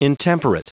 Prononciation du mot intemperate en anglais (fichier audio)
Prononciation du mot : intemperate